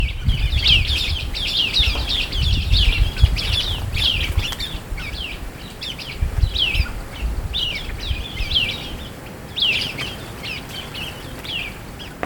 Slender-billed White-eye
Palm Glen, Norfolk Island National Park, Norfolk Island, South Pacific Ocean, Australia
Zosterops tenuirostris
White-eye Slender-billed PALM GLEN NF AUS call [B] ETSJ_LS_71910.mp3